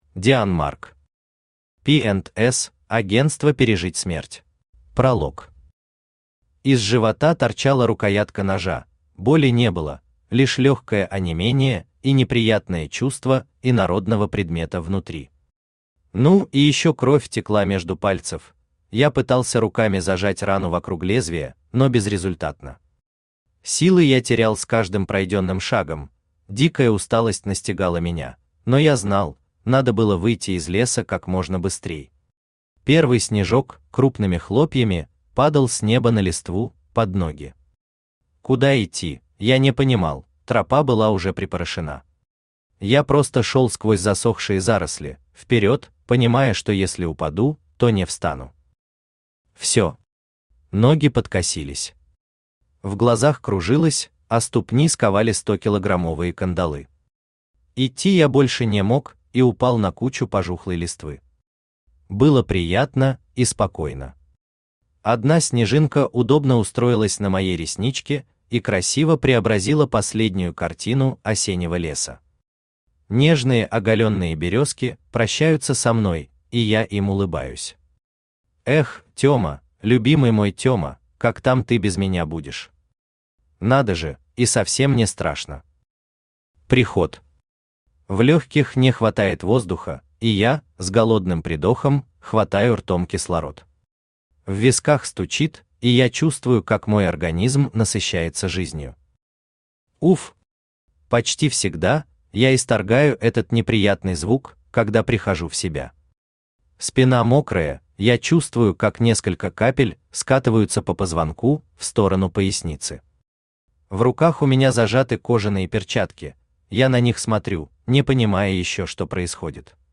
Aудиокнига P&S – агентство «пережить смерть» Автор Дионмарк Читает аудиокнигу Авточтец ЛитРес.